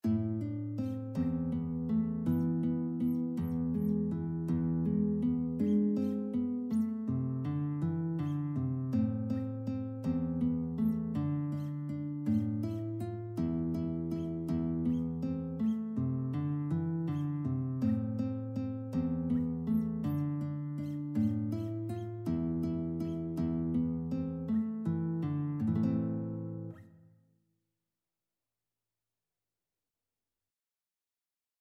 3/4 (View more 3/4 Music)
E3-F5
One in a bar .=c.54
Guitar  (View more Easy Guitar Music)
Classical (View more Classical Guitar Music)